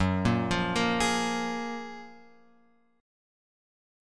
F# Major 9th